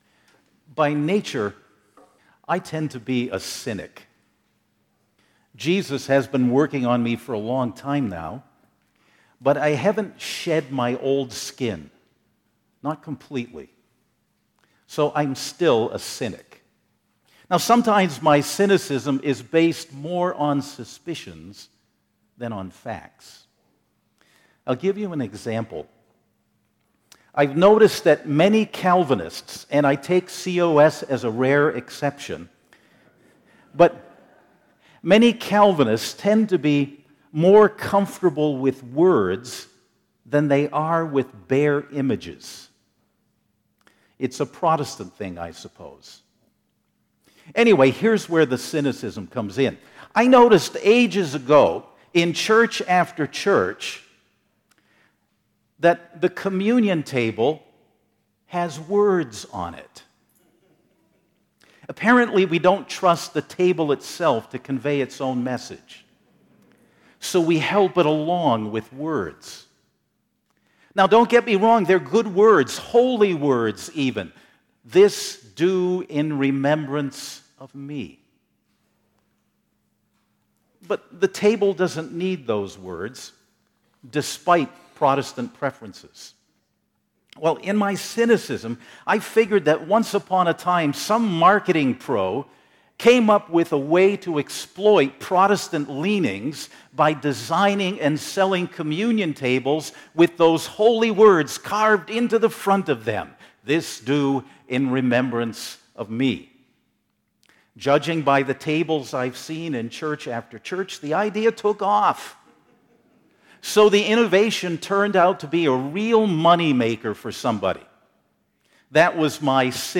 2018 Sermons